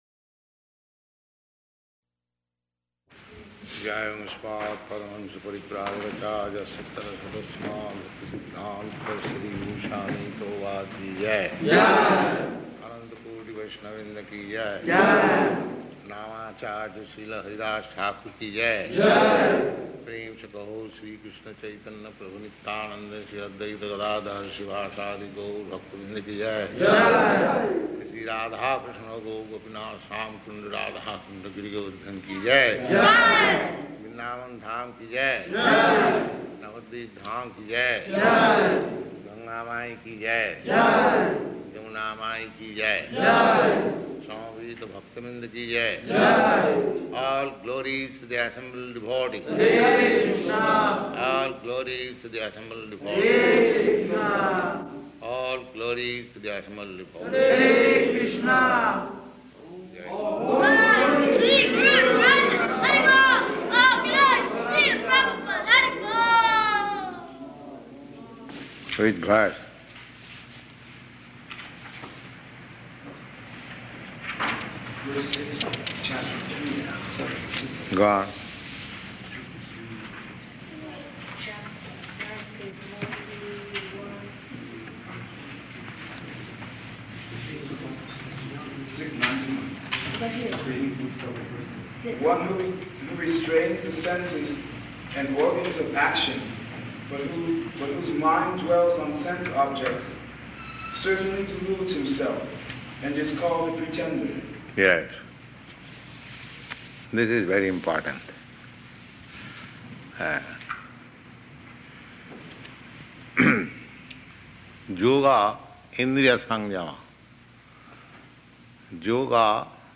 Bhagavad-gītā 3.6-10 --:-- --:-- Type: Bhagavad-gita Dated: December 23rd 1968 Location: Los Angeles Audio file: 681223BG-LOS_ANGELES.mp3 Prabhupāda: [ prema-dhvani ] Jaya [devotees offer obeisances] Read verse.